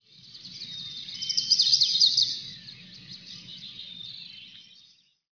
bird5.wav